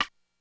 Index of /90_sSampleCDs/300 Drum Machines/Korg DSS-1/Drums01/04
Rimshot_63.wav